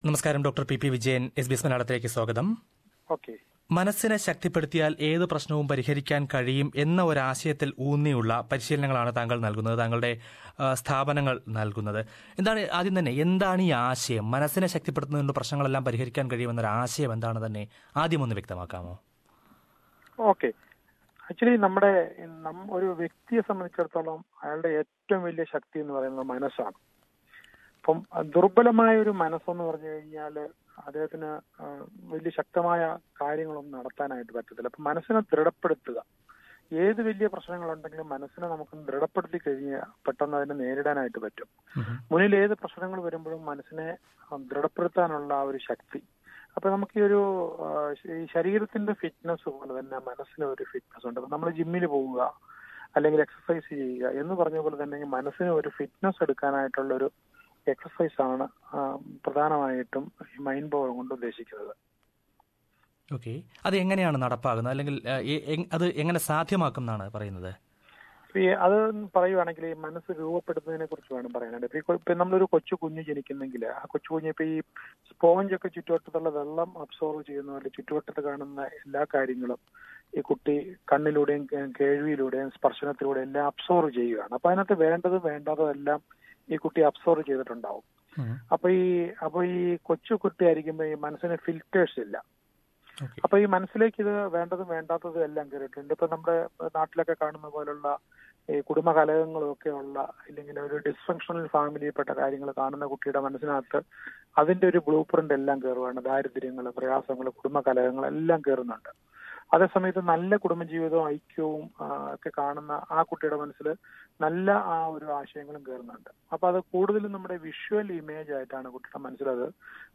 അഭിമുഖം